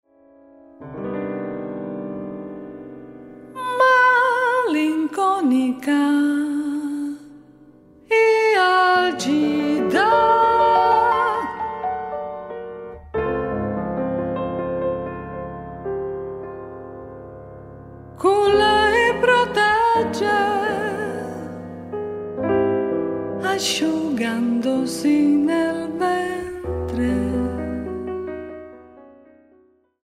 for female voice and two pianists
a Steinway mod.D 274 piano.